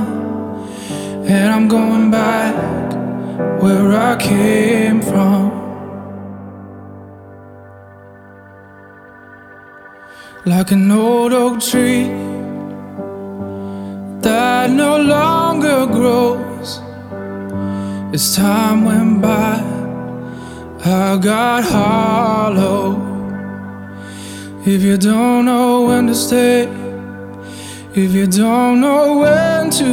• Singer/Songwriter